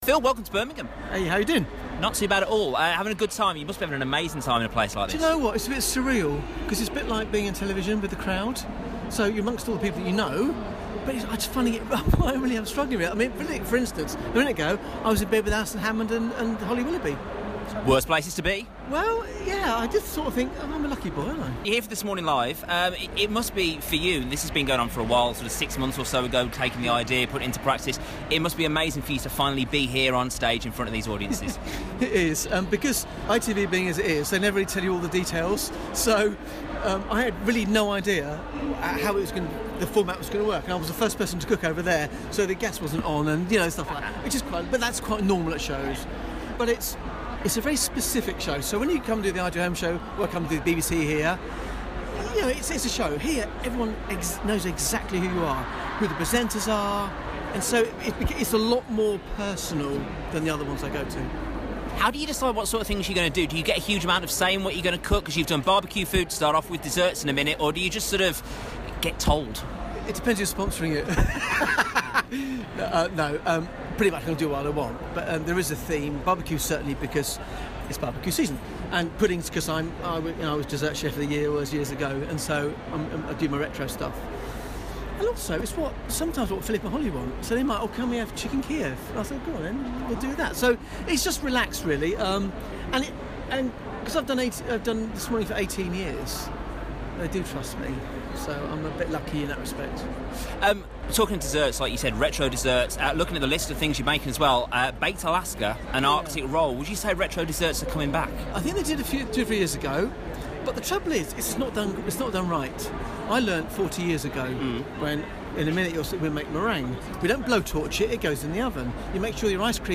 I popped out to This Morning Live at Birmingham's NEC, where I grabbed a chat with veteran chef, Phil Vickery!